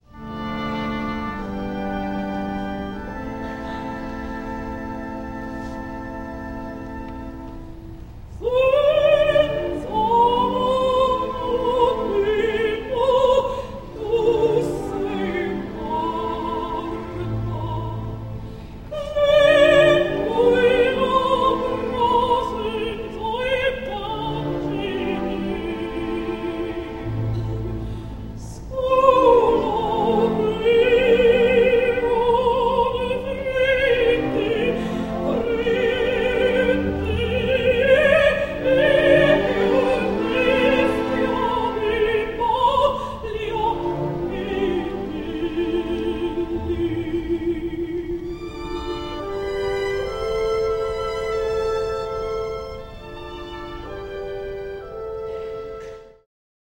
Gift of Georgia O’Keeffe and Giacomo Puccini: Suor Angelica (Sister Angelica), “Senza Mama.” Performed by Leona Mitchell, with the San Francisco Opera Orchestra conducted by Nello Santi